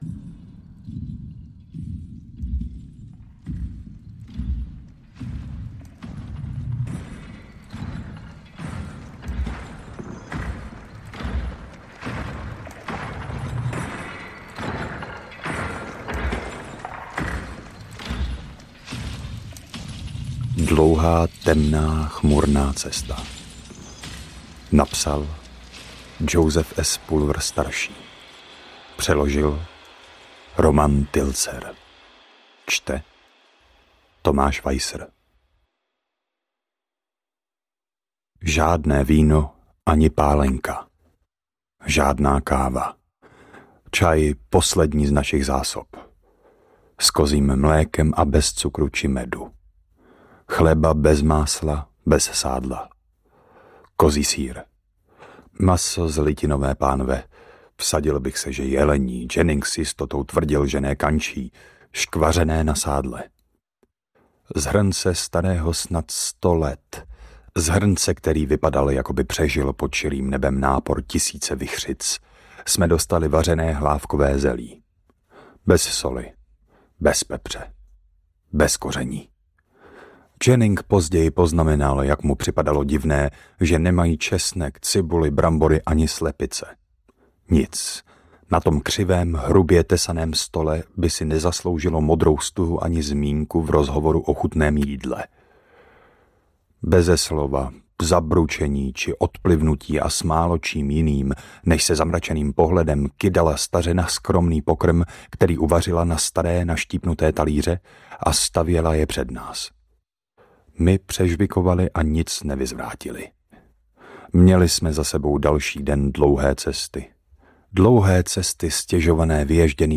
Dlouhá, temná, chmurná cesta audiokniha
Ukázka z knihy